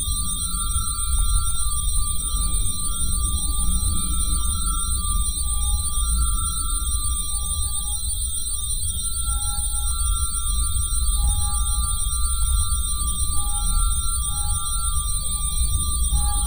newPortal.wav